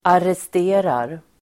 Uttal: [arest'e:rar]